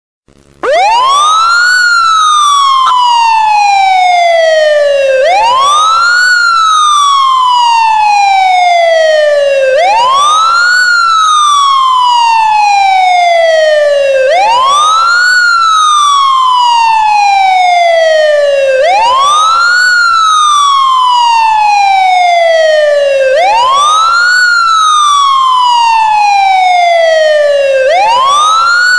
ambulance-alert_24918.mp3